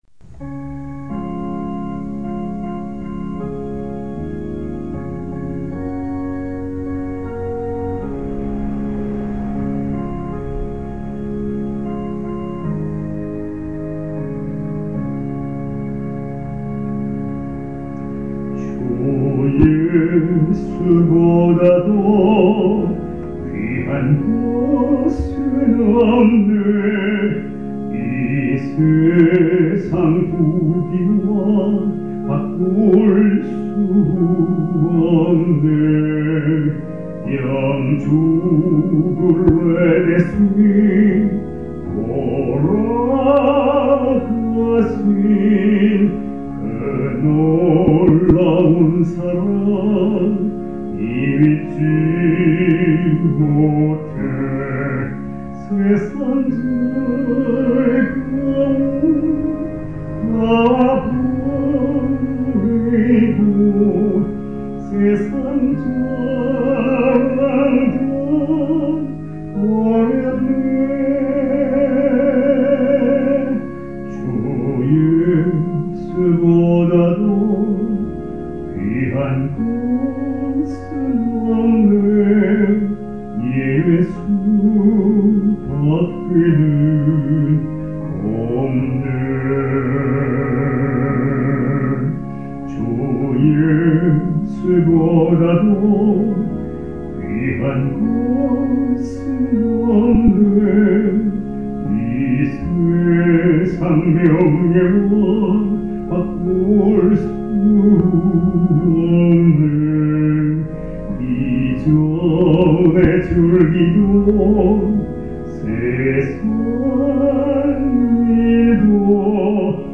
부귀와 명예, 그리고 행복도~ 늘 부족한 맘으로 불러 봅니다.